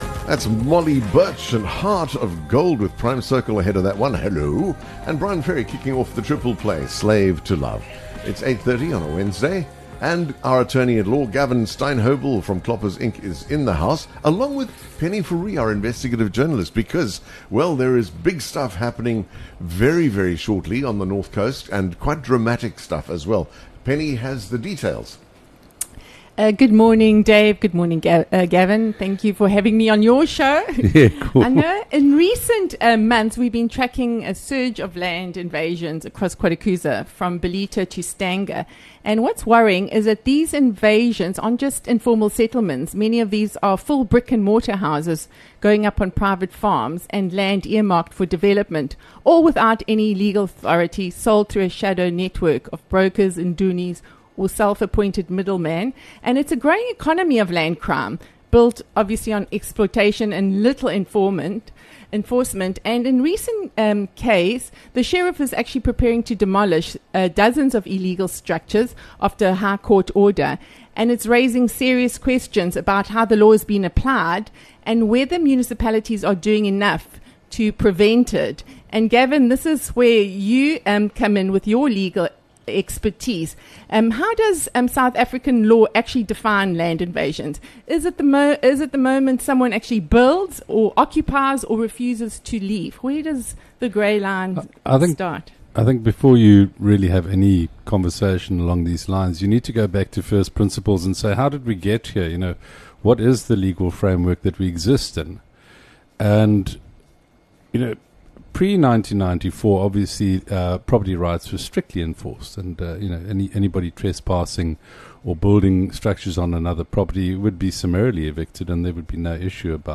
Broadcasting live from the heart of Ballito, The Morning Show serves up a curated mix of contemporary music and classic hits from across the decades, alongside interviews with tastemakers & influencers, plus a healthy dose of local news & views from the booming KZN North Coast.